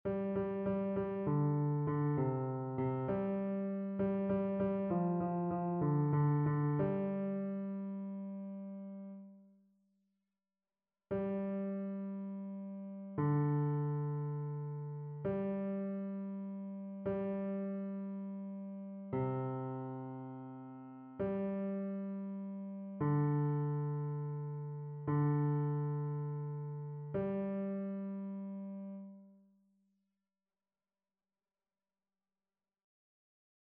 Basse
annee-abc-fetes-et-solennites-assomption-de-la-vierge-marie-psaume-44-basse.mp3